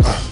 Future Honest Kick.wav